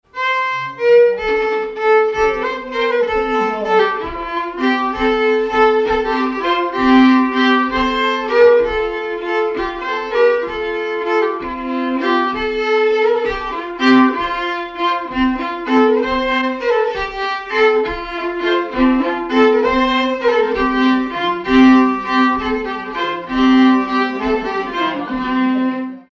circonstance : bal, dancerie
Pièce musicale inédite